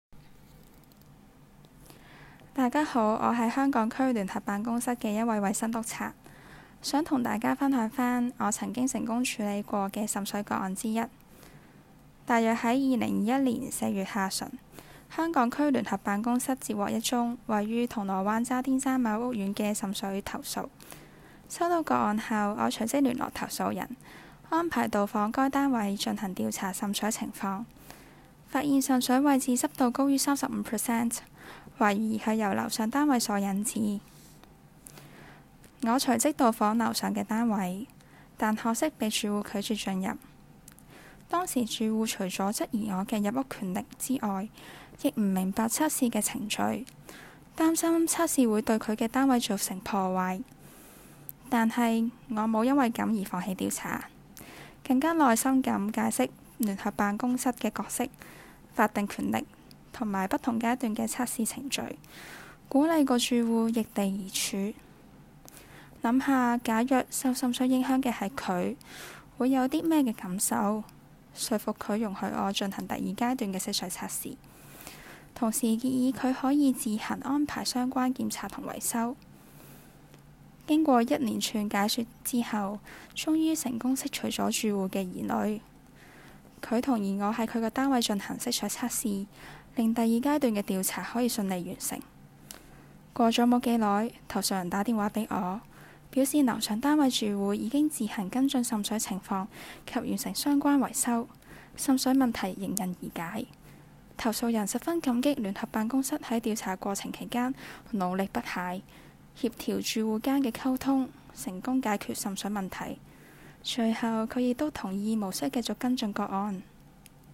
Case No. Year (Region) The voice of Joint Office investigating officer